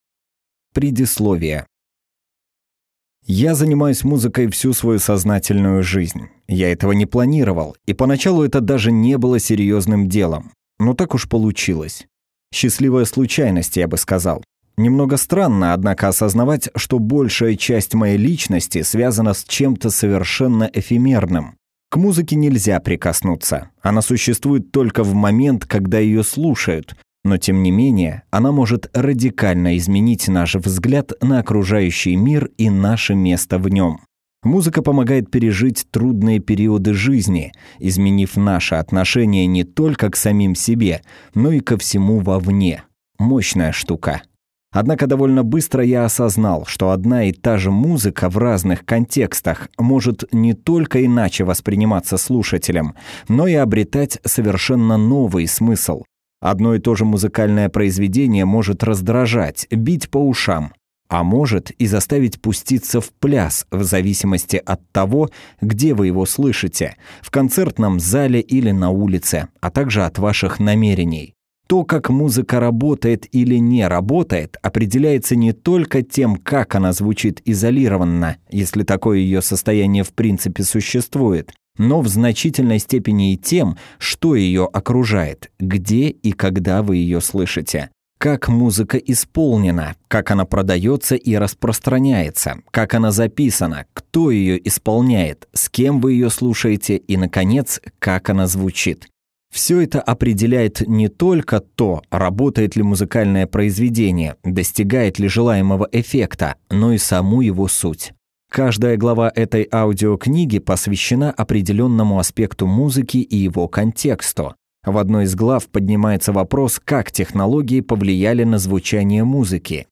Аудиокнига Как работает музыка | Библиотека аудиокниг